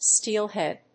音節stéel・hèad 発音記号・読み方
/ˈstiˌlhɛd(米国英語), ˈsti:ˌlhed(英国英語)/